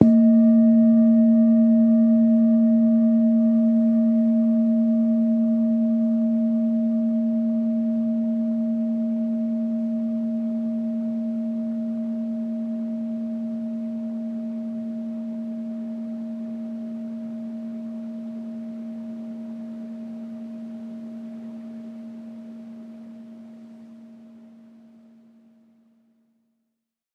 jan-bowl2-soft-A2-mf.wav